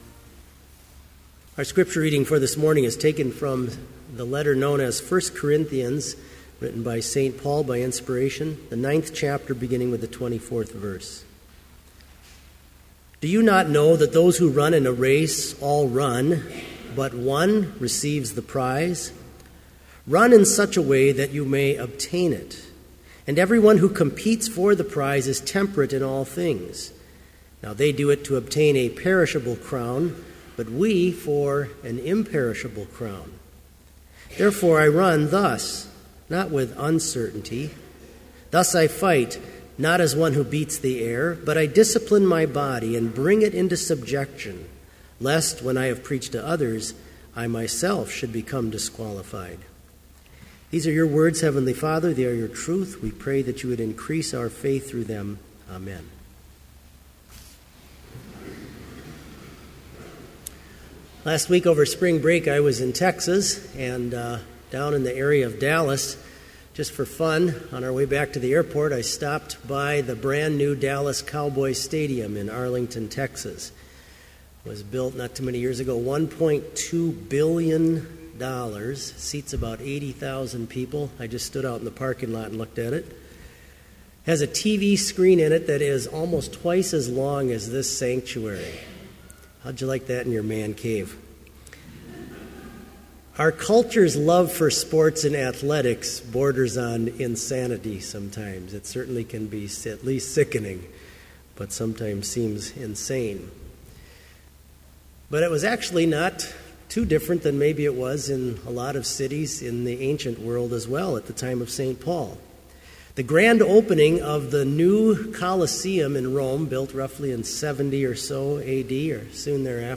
Complete Service
• Hymn 249, Fight the Good Fight
• Homily
This Chapel Service was held in Trinity Chapel at Bethany Lutheran College on Wednesday, March 19, 2014, at 10 a.m. Page and hymn numbers are from the Evangelical Lutheran Hymnary.